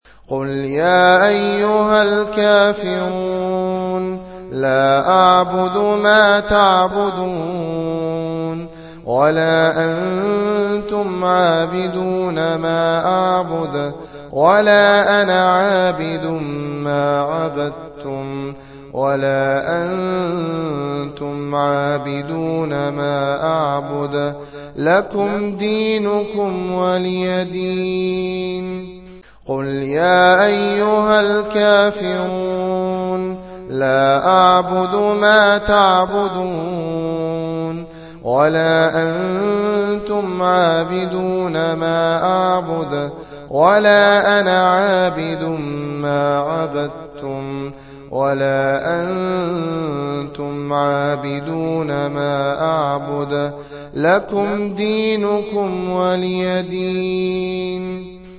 تلاوات
الصنف: تلاوات